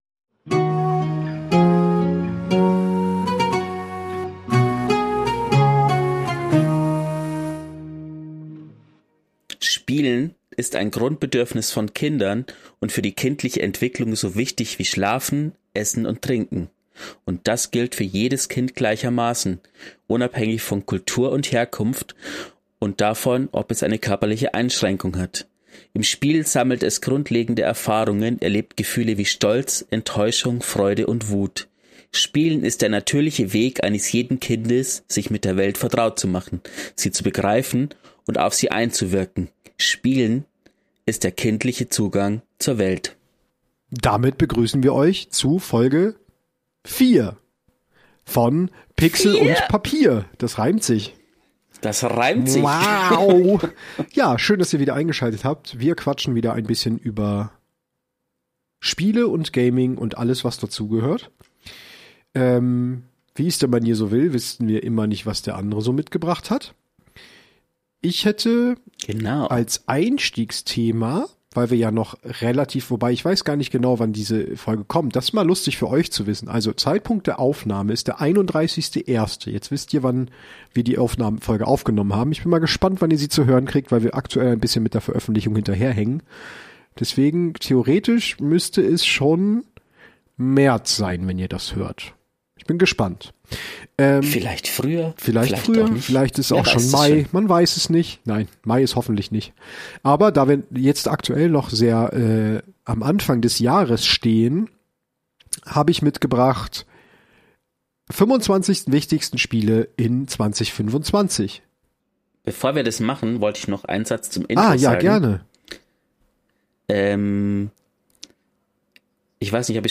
zwei Nerds die gerne Spiele aller Art spielen.